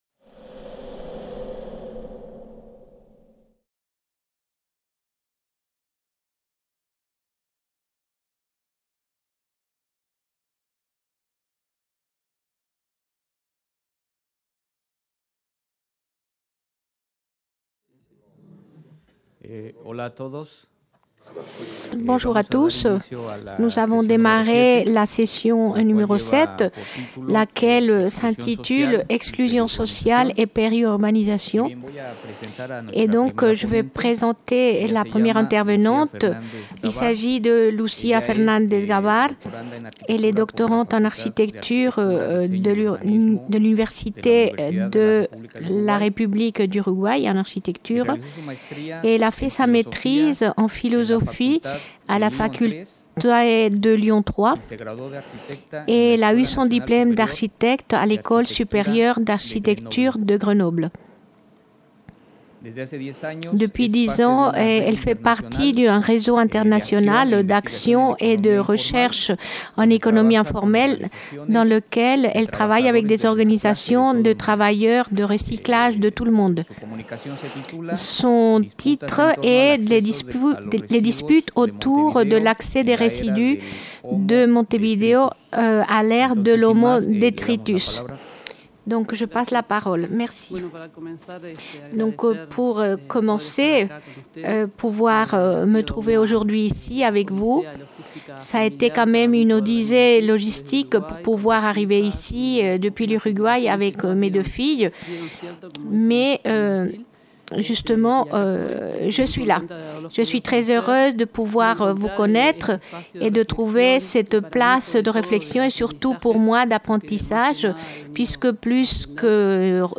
Cette communication a été filmée lors du colloque international "Le droit à Lefebvre" qui s'est déroulé du 29 au 31 mai 2018 à Caen.